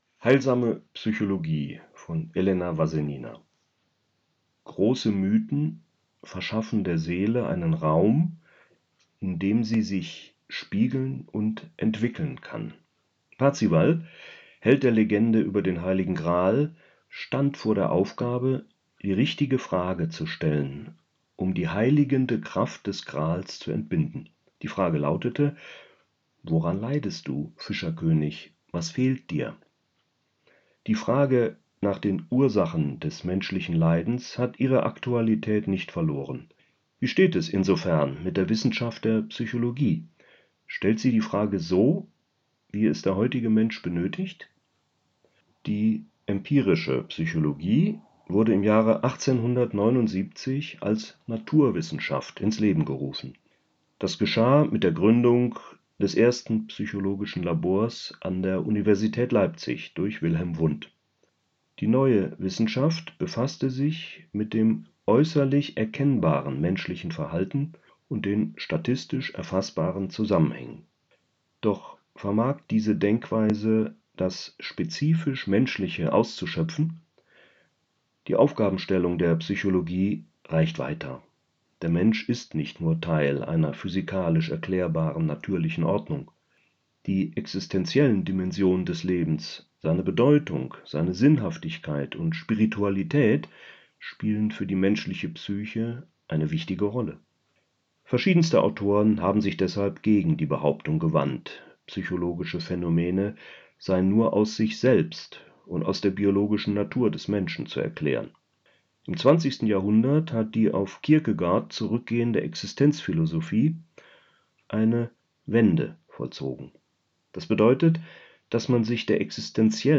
LOGON-Artikel gelesen